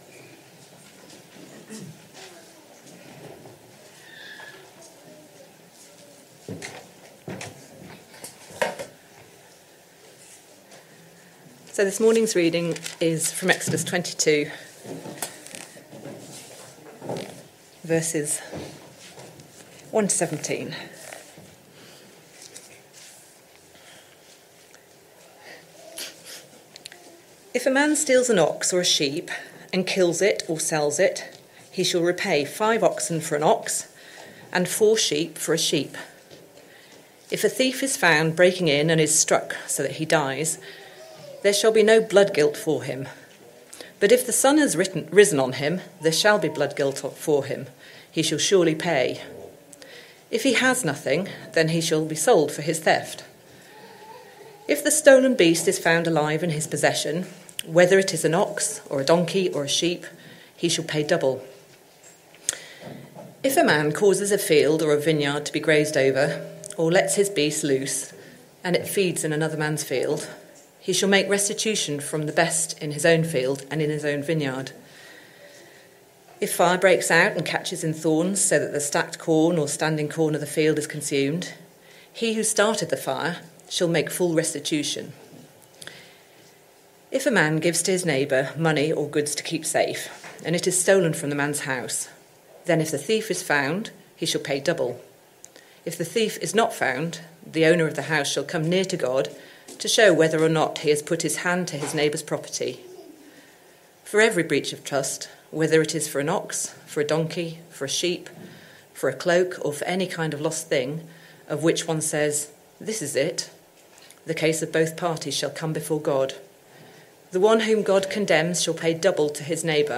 Christ Church Sermon Archive
Sunday Morning Service Sunday 8th June 2025 Speaker